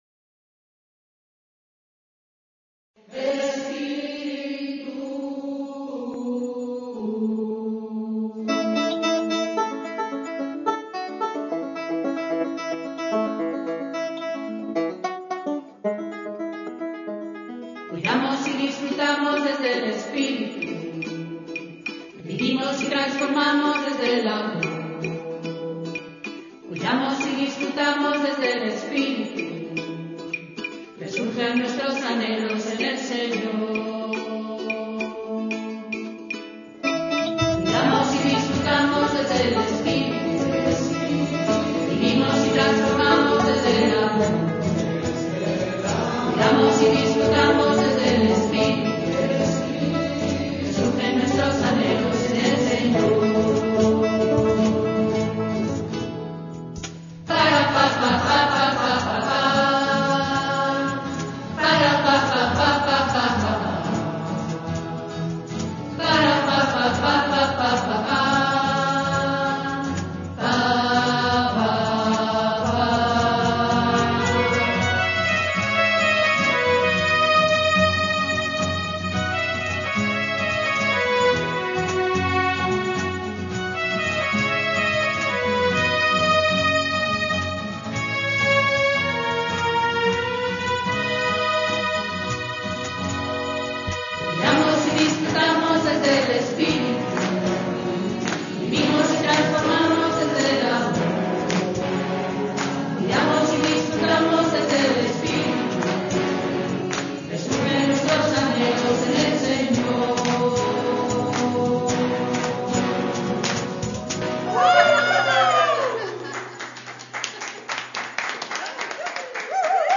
Ha sido grabada en octubre de 2021 por miembros de los coros de las misas de 12 y 8, y de la comunidad Ephetá.